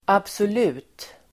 Uttal: [absol'u:t]